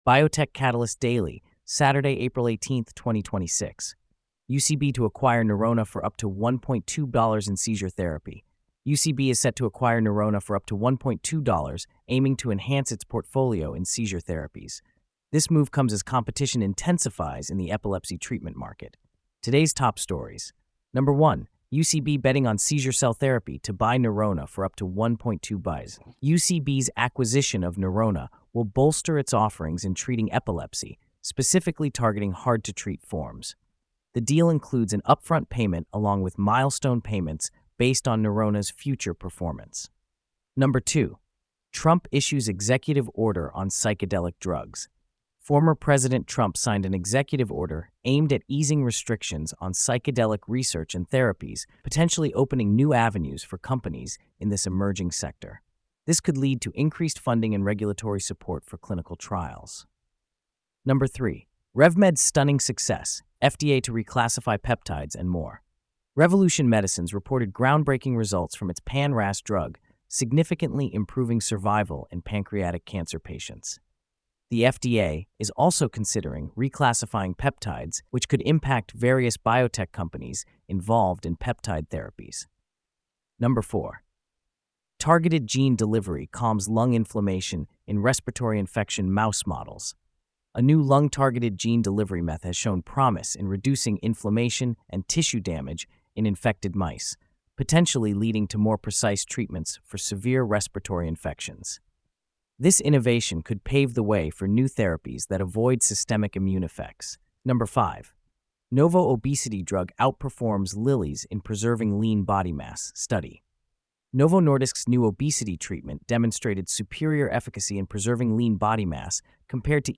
This briefing, read aloud